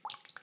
SplashCamera_01.ogg